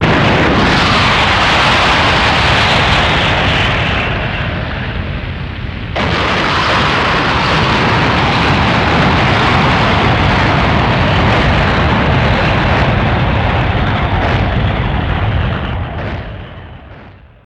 Geyser Blast And Explosive Sustain